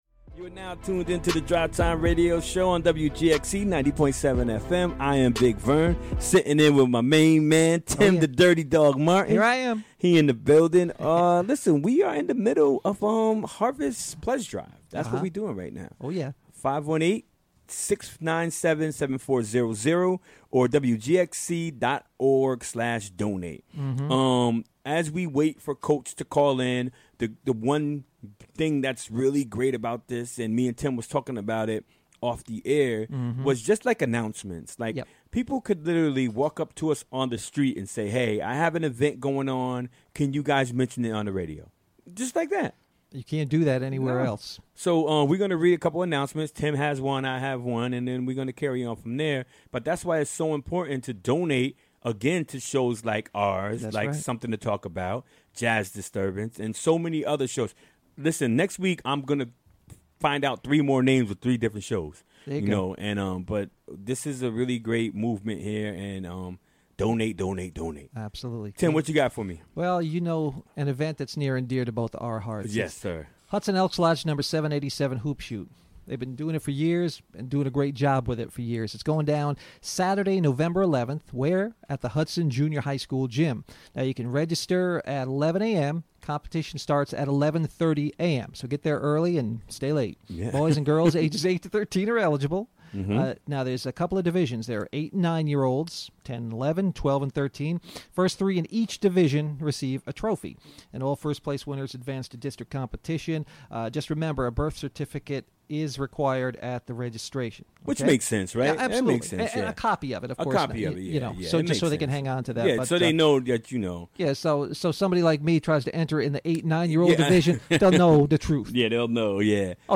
Interviewed
Recorded during the WGXC Afternoon Show Wednesday, October 11, 2017.